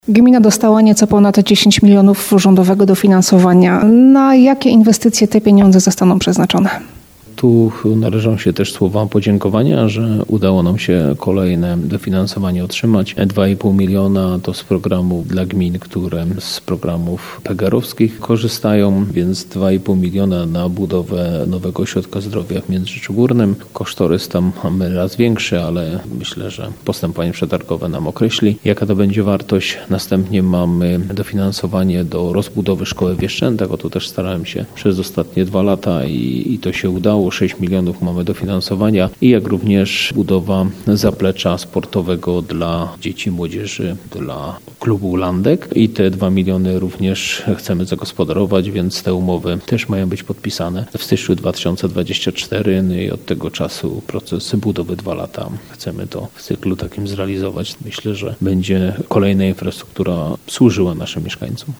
Mówił o tym na naszej antenie wójt Janusz Pierzyna.